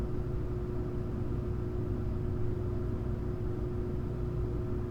hum.ogg